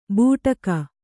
♪ būṭaka